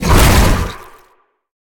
File:Sfx creature hiddencroc chase os 05.ogg - Subnautica Wiki
Sfx_creature_hiddencroc_chase_os_05.ogg